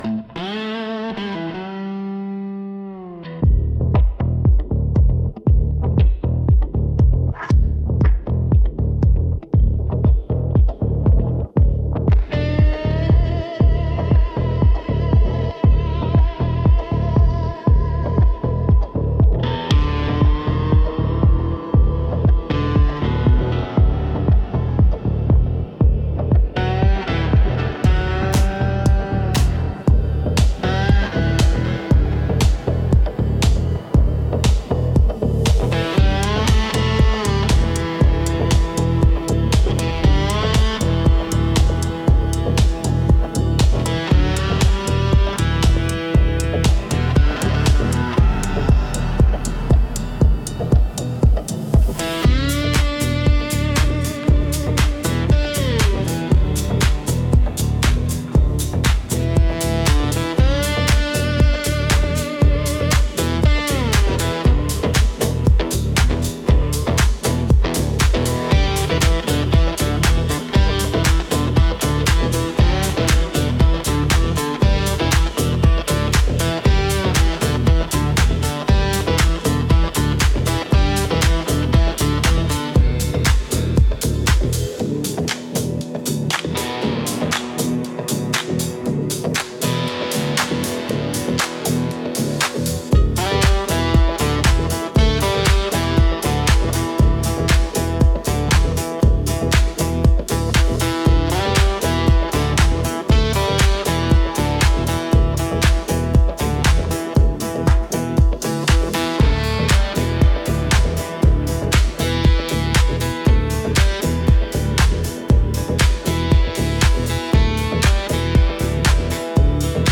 Instrumental - Smoke on Cypress